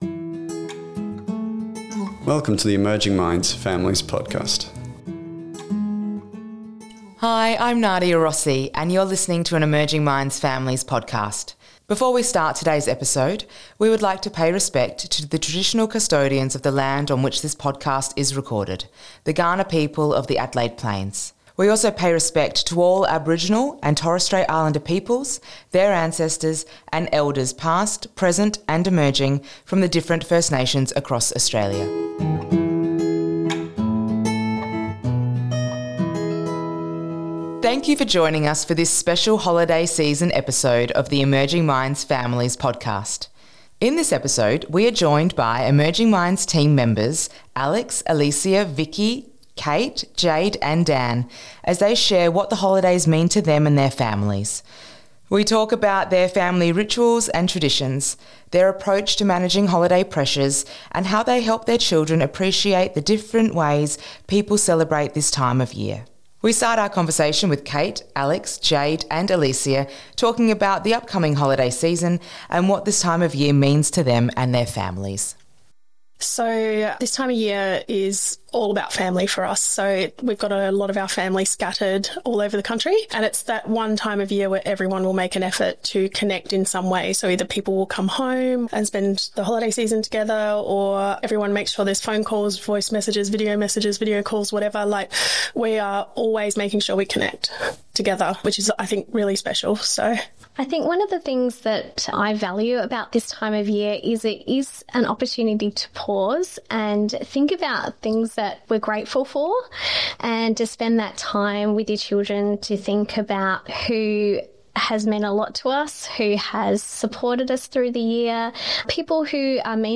talks with Emerging Minds team members